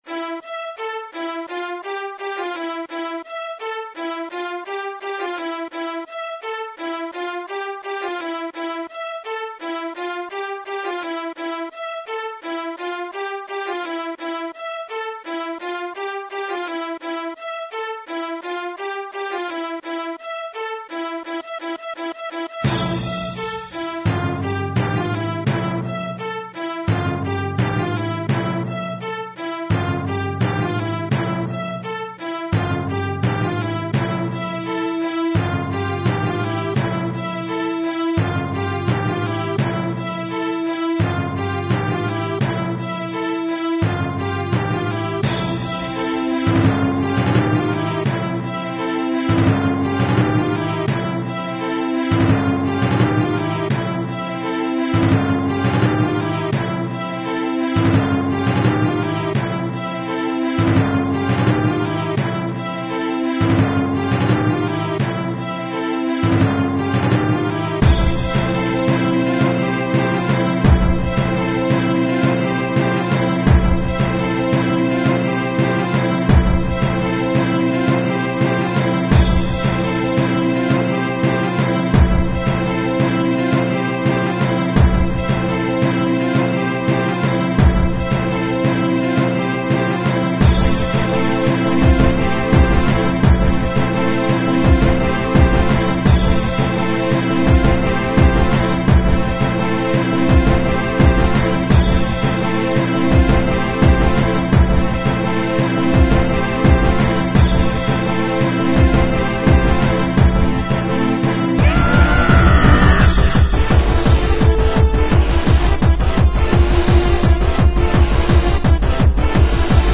Techno
Hardcore